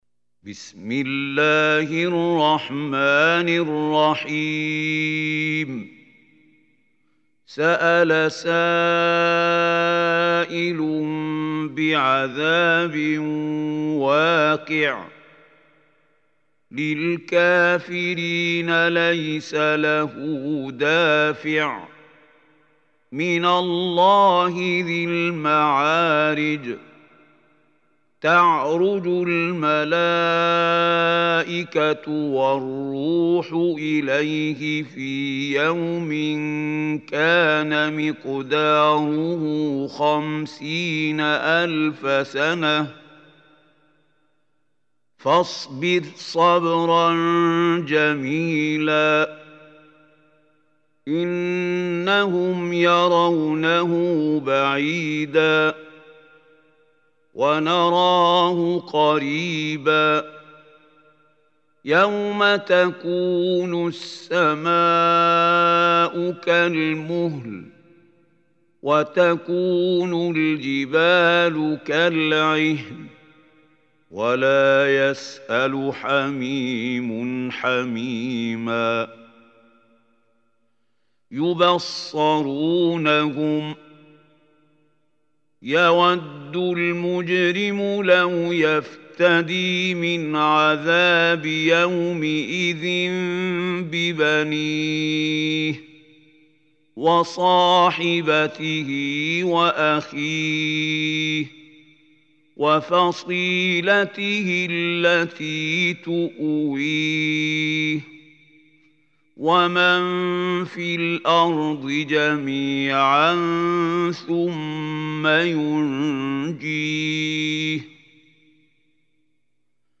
Surah Maarij MP3 Recitation by Khalil Hussary
Surah Maarij is 70 surah of Holy Quran. Listen or play online mp3 tilawat / recitation in Arabic in the beautiful voice of Sheikh Khalil Hussary.